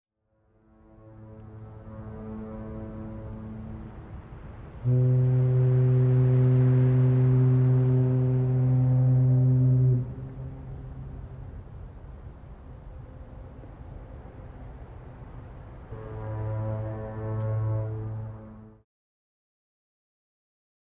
Foghorns ( Golden Gate Bridge ), Distant W Light City And Traffic Background.